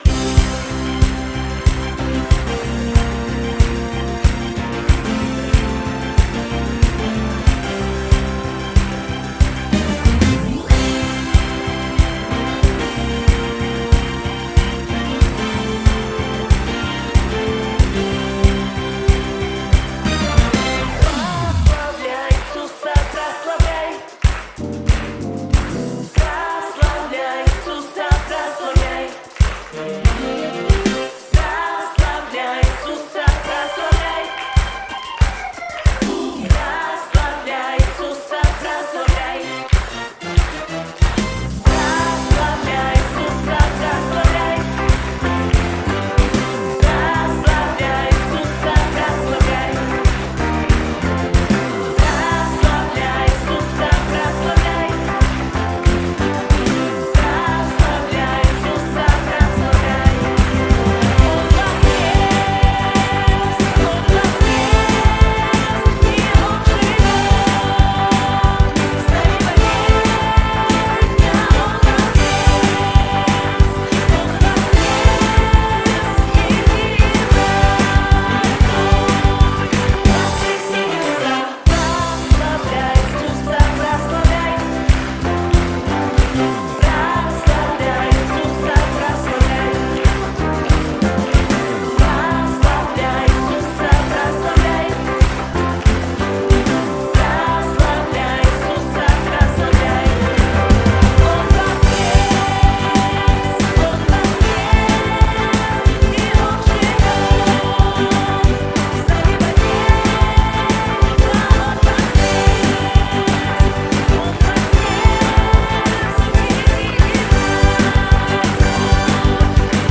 Вокальный диапазон: C1->A1
188 просмотров 258 прослушиваний 5 скачиваний BPM: 186